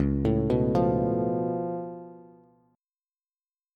Db+7 Chord
Listen to Db+7 strummed